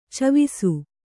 ♪ cavisu